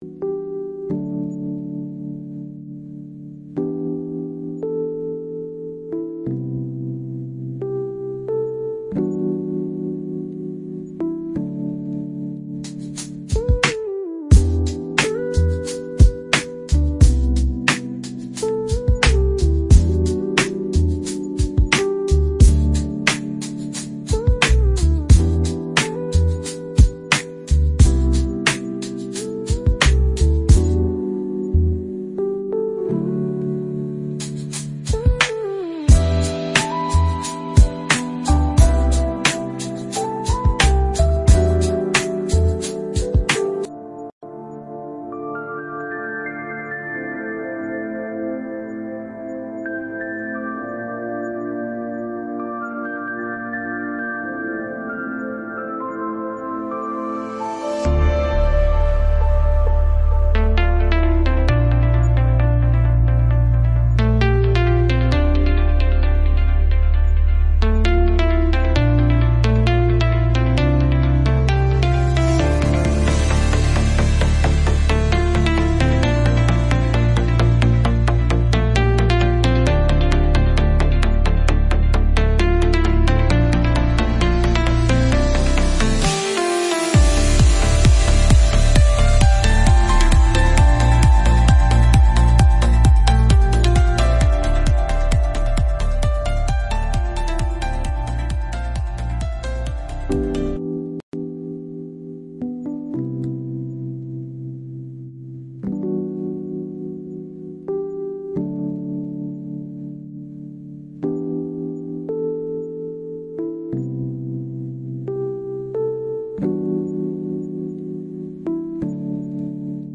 Sermons | Garden of Eden Ministries
Pastors share the testimonies of the Memphis Missions Trip.